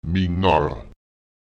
Lautsprecher me’nar [meÈNar] das Alter (etwas, das ein Alter besitzt, kann entweder sis neu oder klóg alt sein)